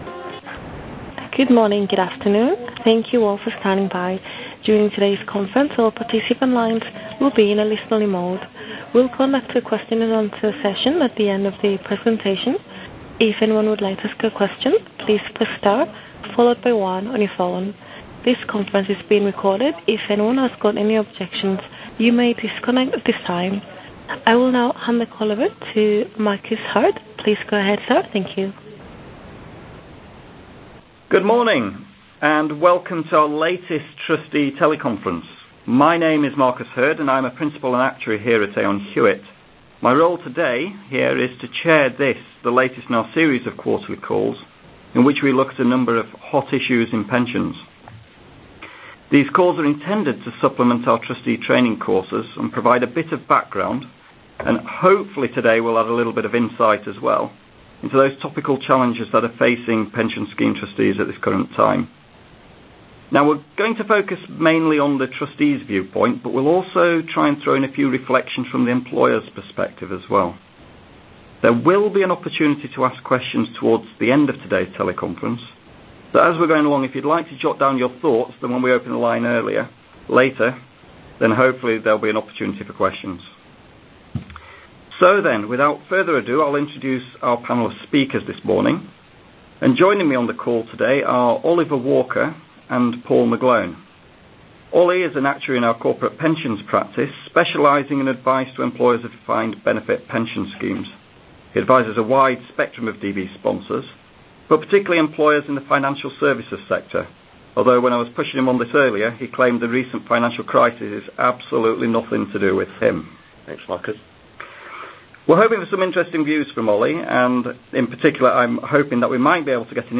Recorded Teleconference: Topical Trustee Issues ¦ United Kingdom
At the teleconference the panel discussed: